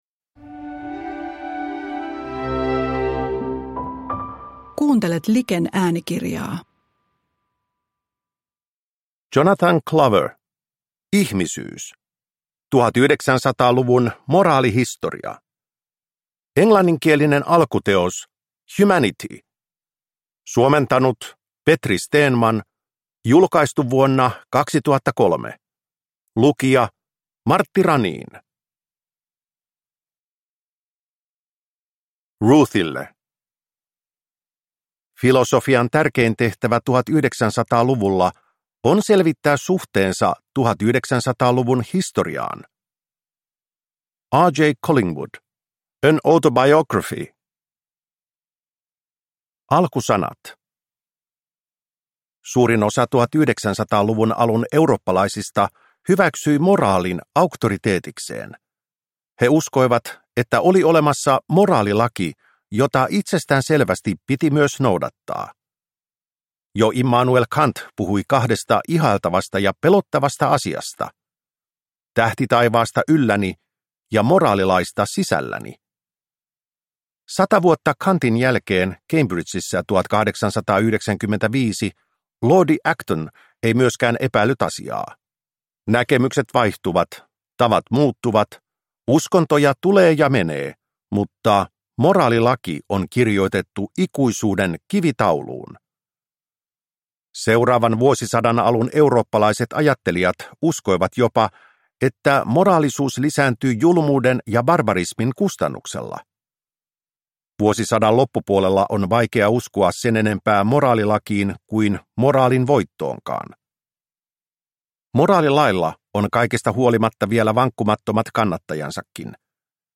Ihmisyys – Ljudbok